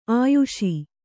♪ āyuṣi